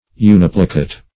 Search Result for " uniplicate" : The Collaborative International Dictionary of English v.0.48: Uniplicate \U*nip"li*cate\, a. [Uni- + plicate.] Having, or consisting of, but one fold.